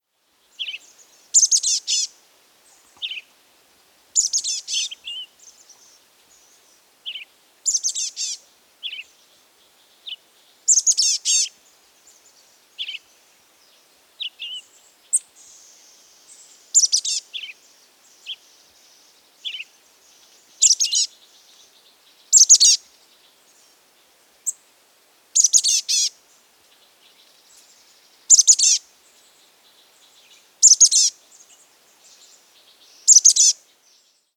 Chestnut-backed Chickadee
How they sound: Like many chickadees, they give the chickadee-dee-dee call, but in addition to that they tend to sing a series of “gargle” calls.
Chestnut-backed_Chickadee_2_CA_Chickadee_calls.mp3